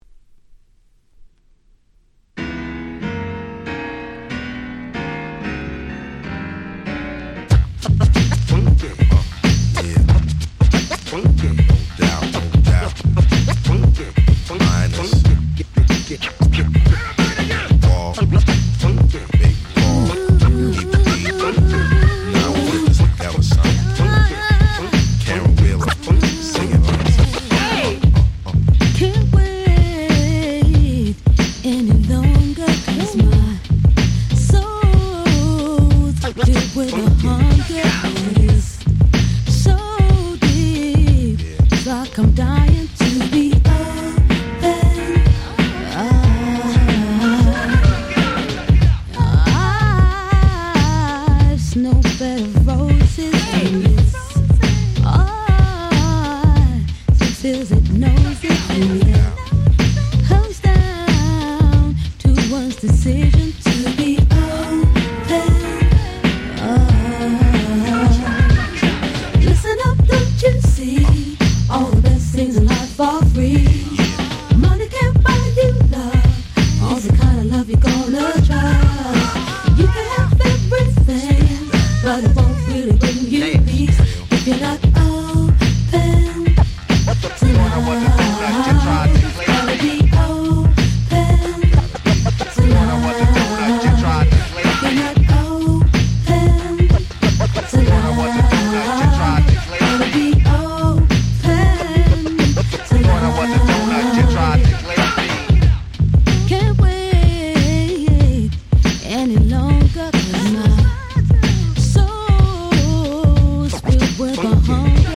01' Nice R&B !!